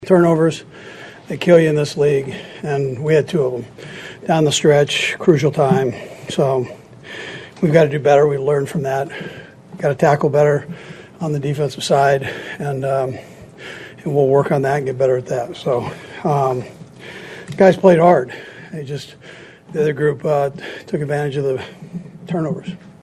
Chiefs Coach Andy Reid after the game.